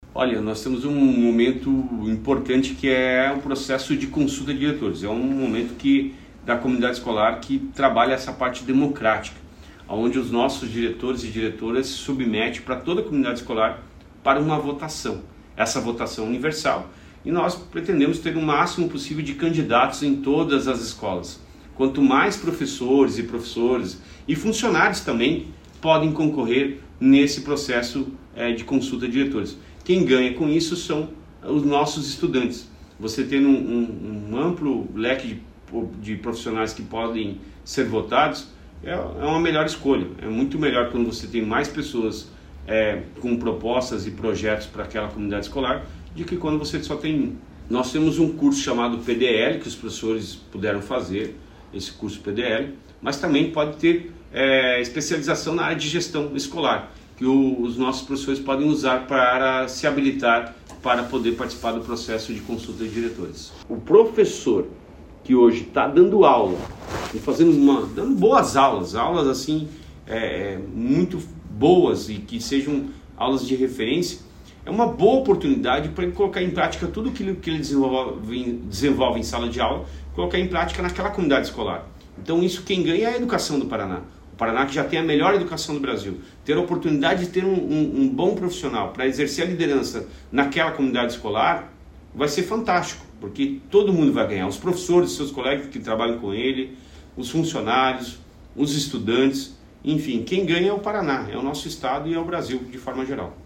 Sonora do secretário da Educação, Roni Miranda, sobre a prorrogação do prazo para se candidatar à diretoria de escolas estaduais